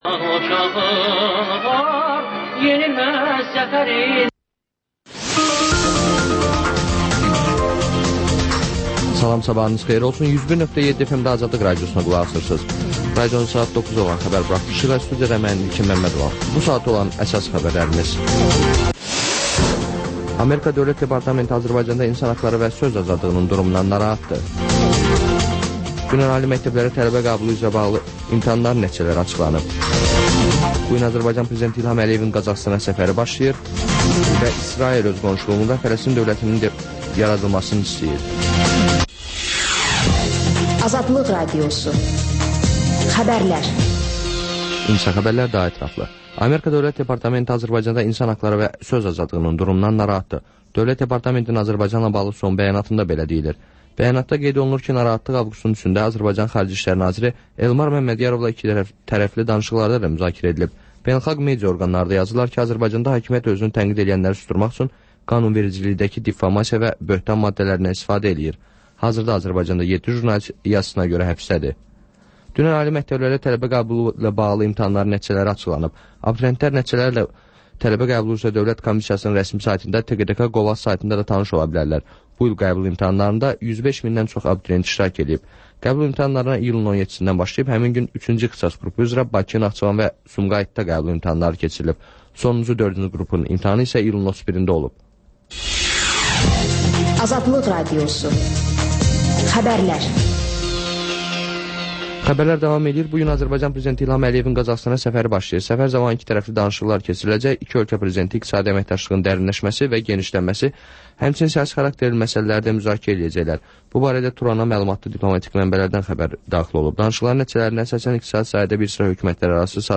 Xəbər-ətər: xəbərlər, müsahibələr, sonra TANINMIŞLAR verilişi: Ölkənin tanınmış simalarıyla söhbət